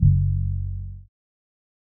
Only 1 [808].wav